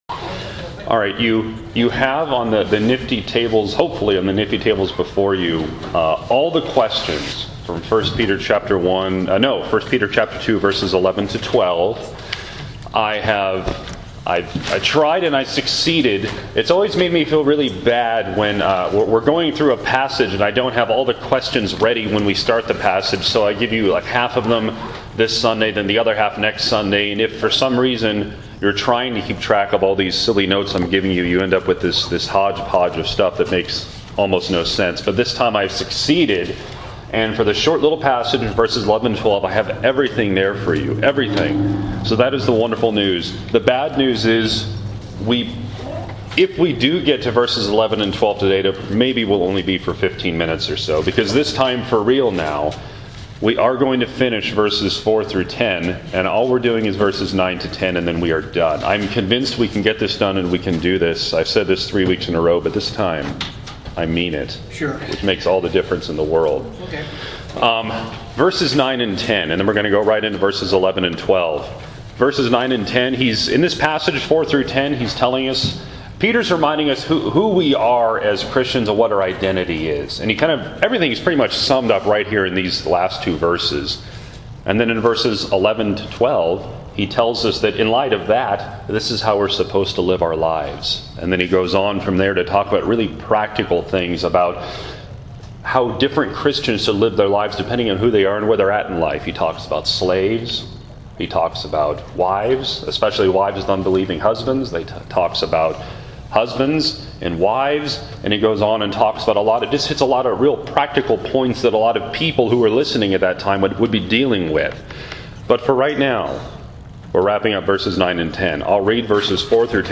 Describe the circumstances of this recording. This past Sunday, we wrapped up our discussion on this passage (1 Peter 2:4-10) with the last two verses (vv.9-10).